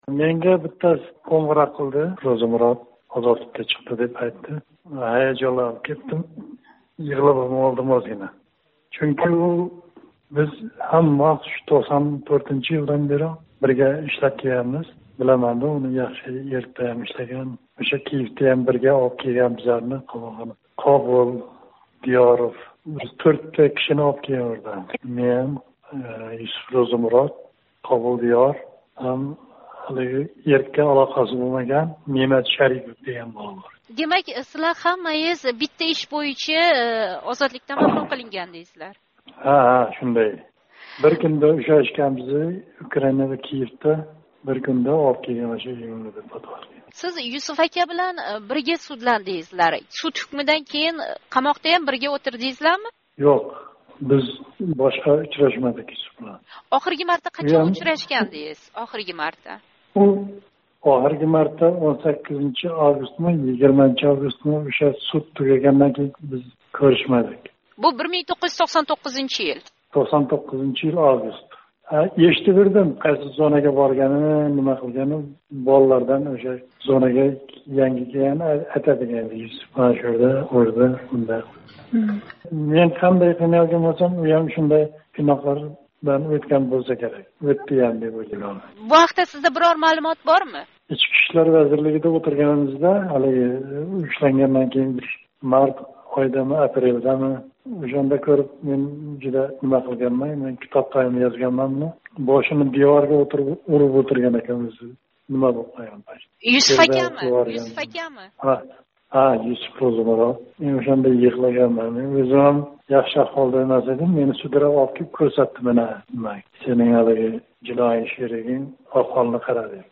билан Озодлик радиоси